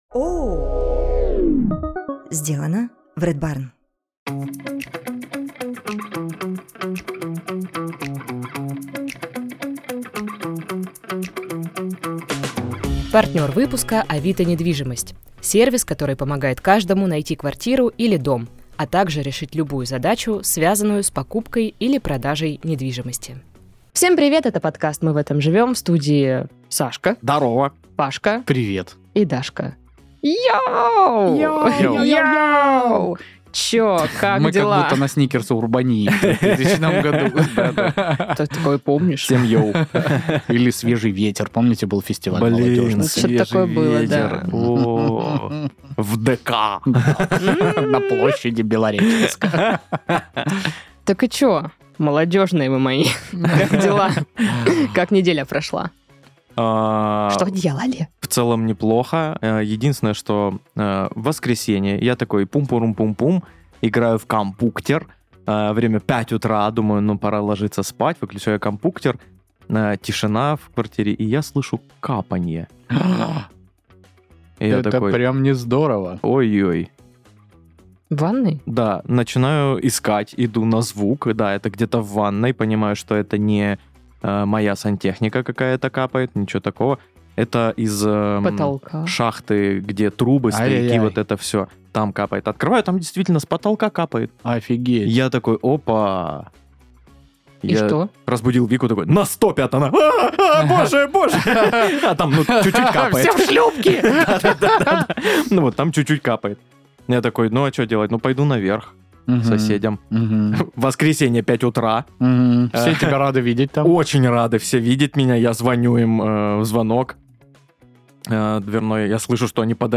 собираются в студии, чтобы обсудить забавные новости, смешные заголовки и повспоминать истории из жизни.